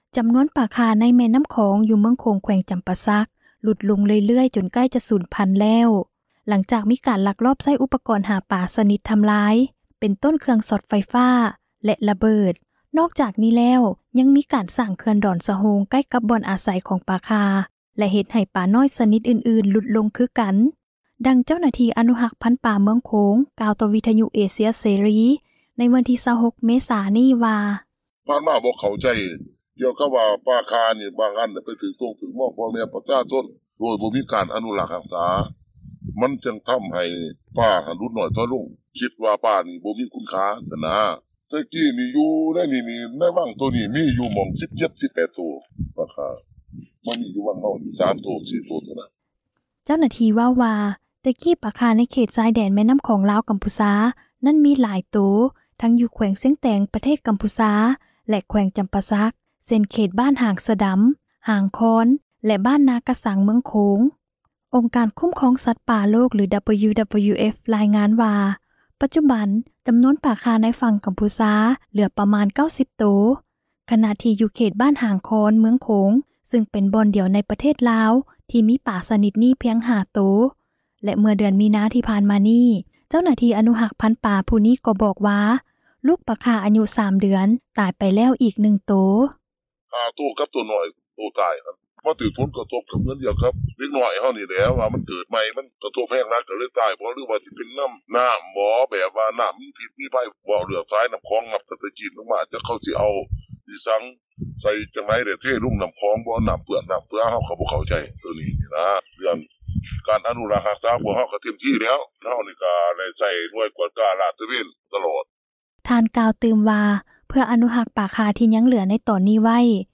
ຈຳນວນປ່າຂ່າ ໃນແມ່ນໍ້າຂອງ ຢູ່ເມືອງໂຂງ ແຂວງຈຳປາສັກ ຫຼຸດລົງເລື້ອຍໆ ຈົນໃກ້ສູນພັນແລ້ວ ຫຼັງຈາກມີການລັກລອບ ໃຊ້ອຸປກອນ ຫາປາ ຊນິດທຳລາຍລ້າງ ເປັນຕົ້ນເຄື່ອງຊ໋ອດໄຟຟ້າ ແລະຣະເບີດ ນອກຈາກນີ້ແລ້ວ ຍັງມີການ ສ້າງເຂື່ອນດອນສະໂຮງ ໃກ້ກັບບ່ອນຢູ່ ອາສັຍຂອງປ່າຂ່າ ແລະເຮັດໃຫ້ປານ້ອຍຊນິດອື່ນໆຫຼຸດລົງເຊັ່ນກັນ, ດັ່ງເຈົ້າໜ້າທີ່ ອະນຸຮັກພັນປາເມືອງໂຂງ ກ່າວຕໍ່ເອເຊັຍເສຣີ ໃນວັນທີ 26 ເມສາ ນີ້ວ່າ: